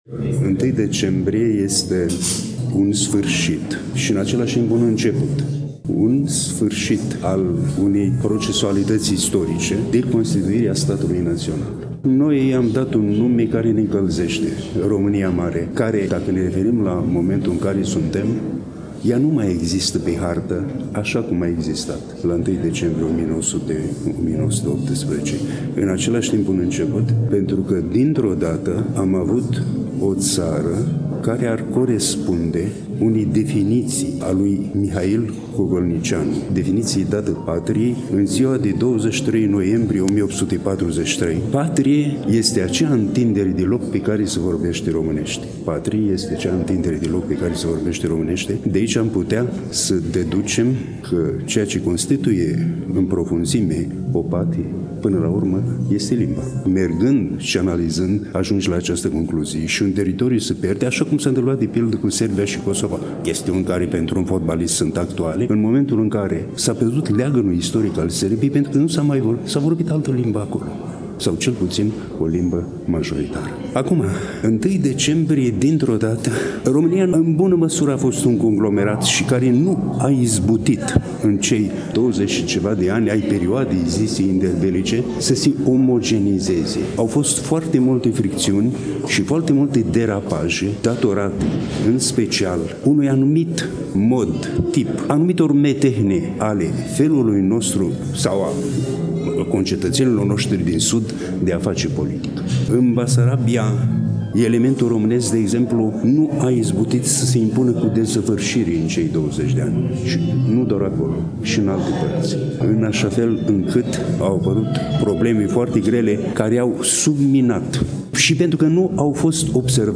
Manifestarea a avut loc în ziua de vineri, 29 noiembrie 2024, începând cu ora 11 în incinta Palatul Braunstein din târgul Iașului.